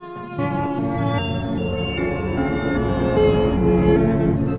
Index of /90_sSampleCDs/USB Soundscan vol.02 - Underground Hip Hop [AKAI] 1CD/Partition E/05-STRINGS